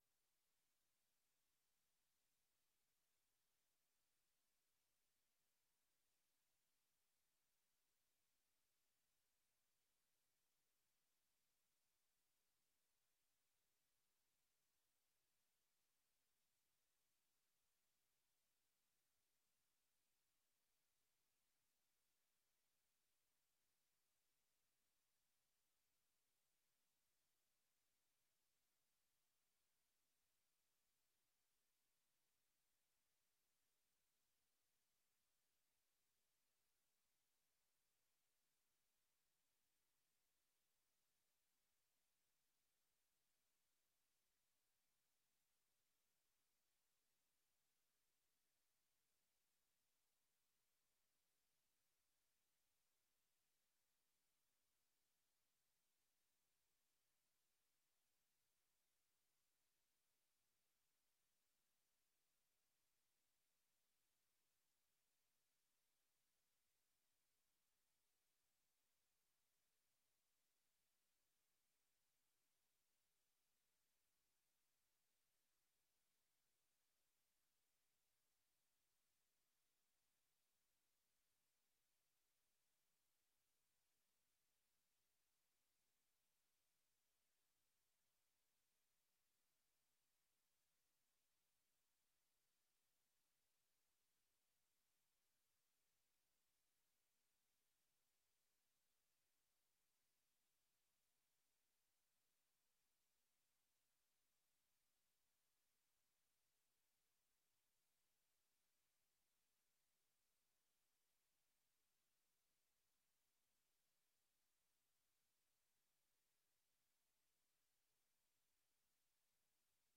Beeldvormende avond Raadzaal 23 september 2024 18:00:00, Gemeente Den Helder